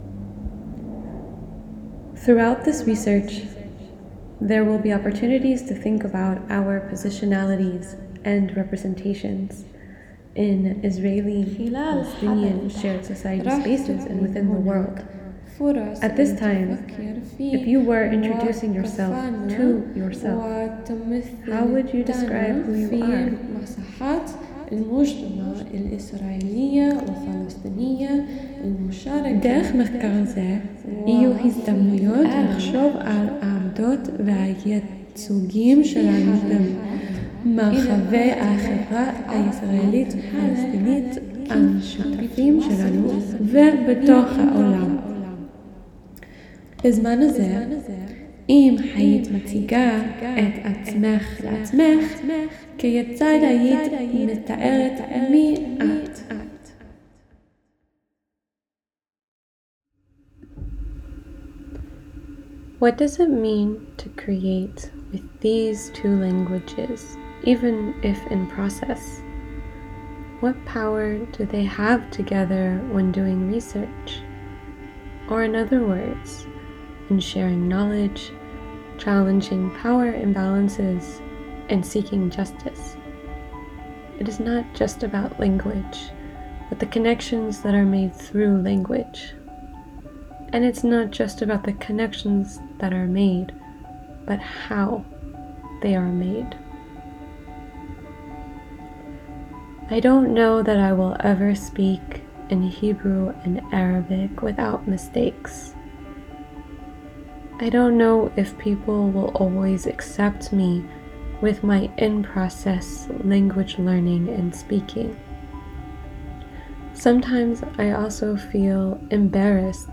Details: In this audio composition, I explore how language learning, when embodied, cultural, affective, and experiential in the pursuit for belonging and justice, is unfinished.
Lyrics and poetry – words – can be resistance work.